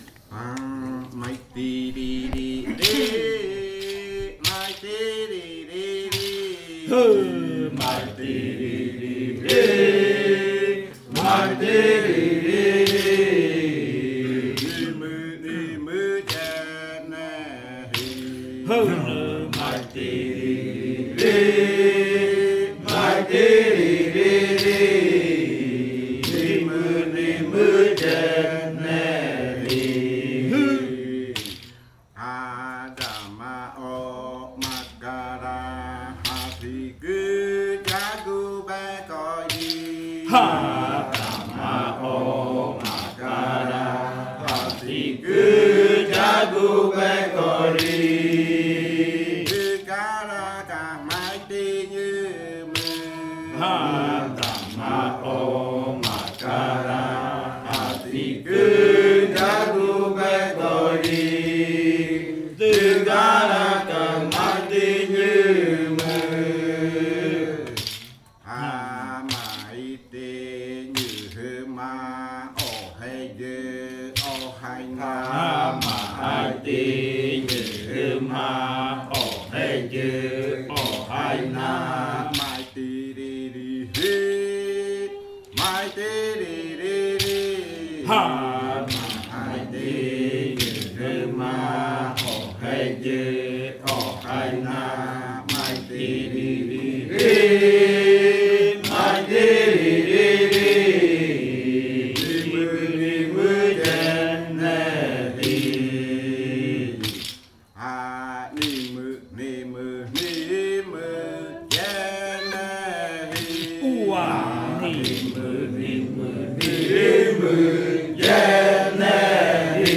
Canto de saltar de la variante jimokɨ
con el grupo de cantores sentado en Nokaido.
with the group of singers seated in Nokaido. This song is part of the collection of songs from the yuakɨ murui-muina ritual (fruit ritual) of the Murui people, a collection that was compiled by the Kaɨ Komuiya Uai Dance Group with support from UNAL, Amazonia campus.